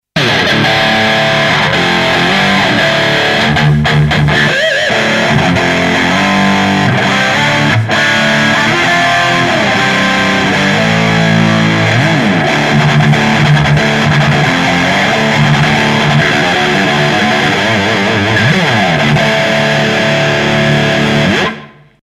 写真はMarshall jcm900ですが、サウンドは別の場所でPEAVEY 5150とESP HORIZON-III Blackで録音したモノです。